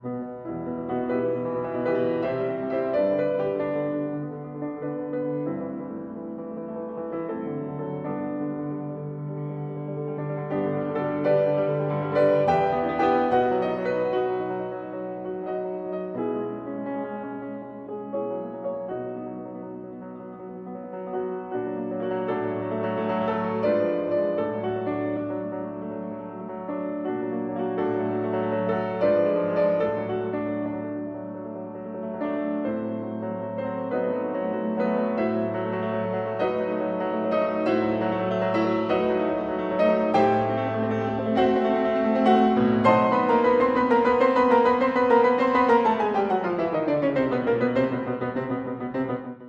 Quasi presto e con fuoco
piano